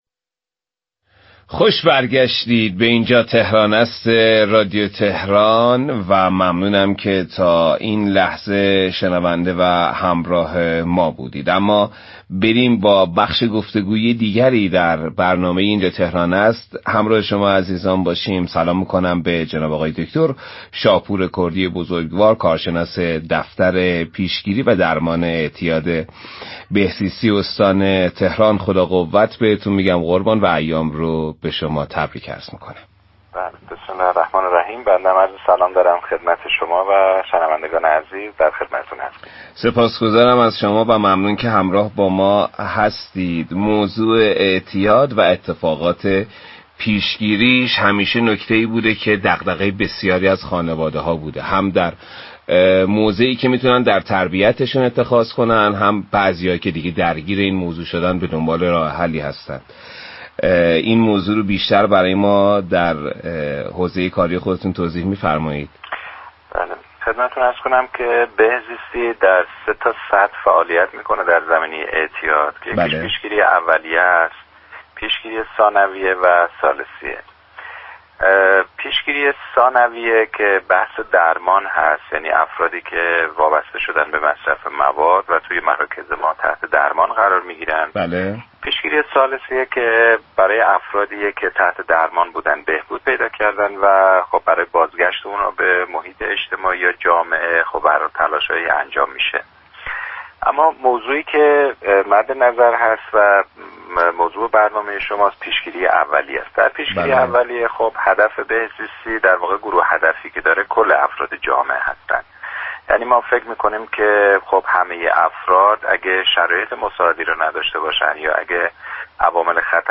در گفتگو با برنامه «اینجا تهران است» رادیو تهران